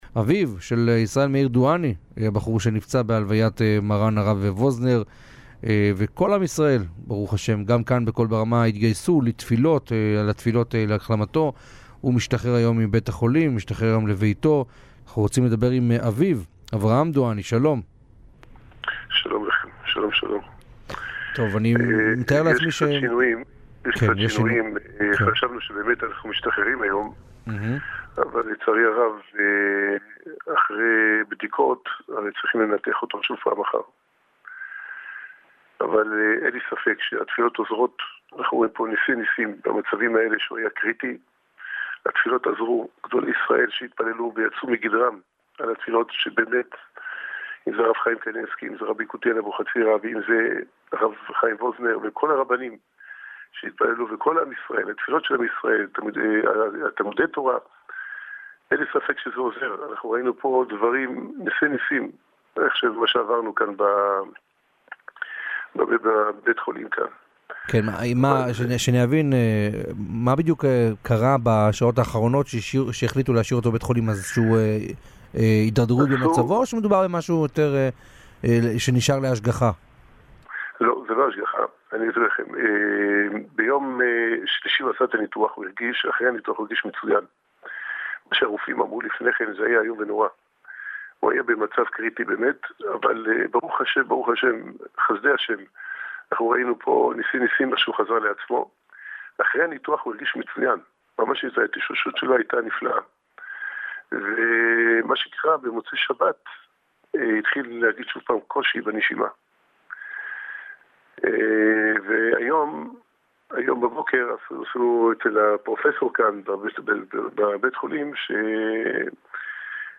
בריאיון לתכנית 'בצהרי היום' המשודרת ברדיו החרדי 'קול ברמה'